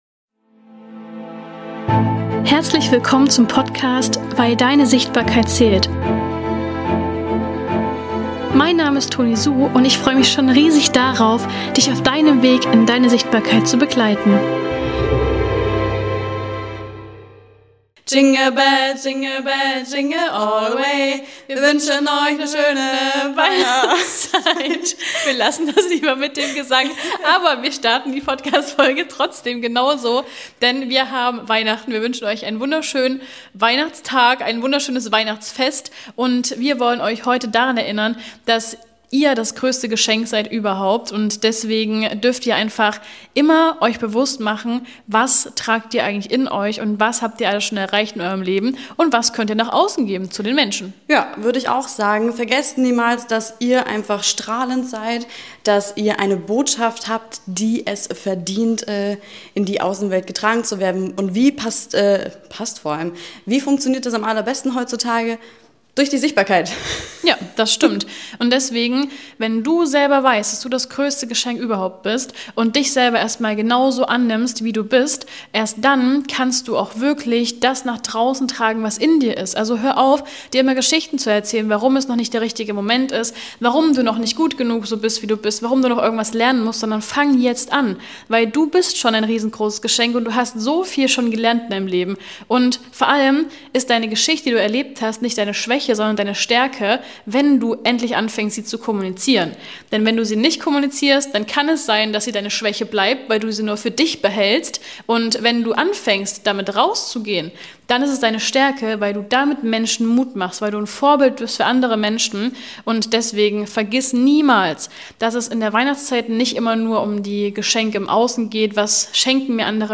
Wir starten mit einem fröhlichen Weihnachtsjingle und lassen